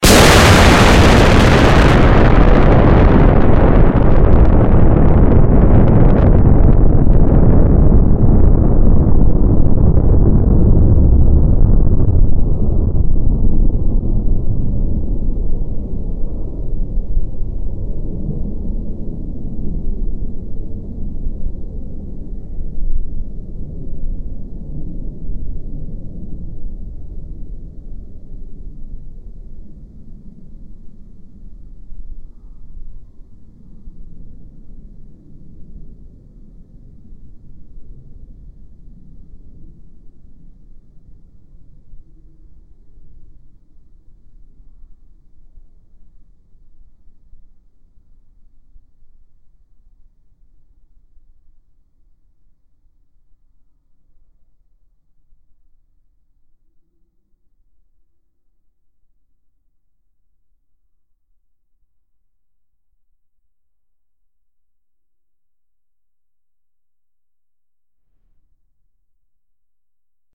nuclearExplosion.mp3